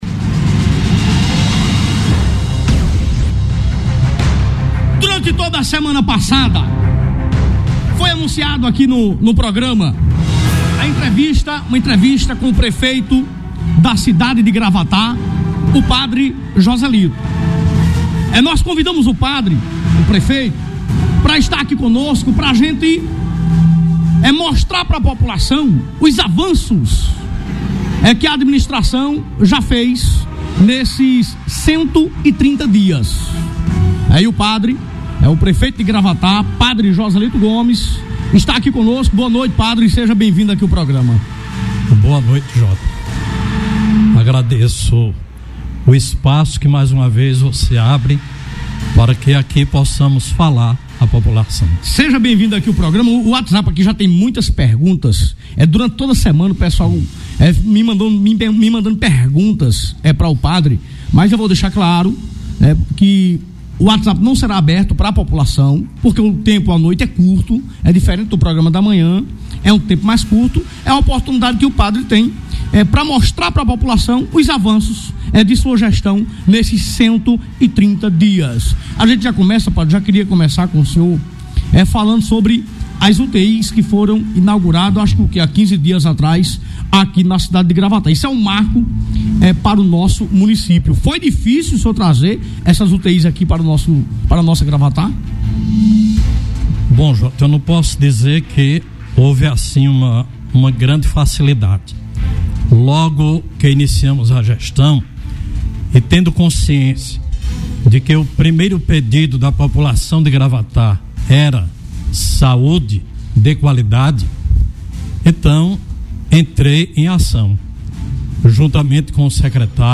PERGUNTA PRÉVIAMENTE GRAVADA: